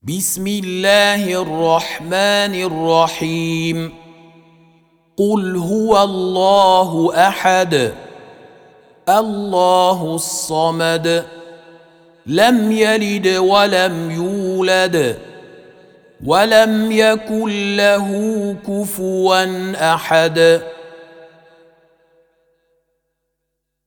Қуръони карим тиловати, Қорилар. Суралар Qur’oni karim tilovati, Qorilar.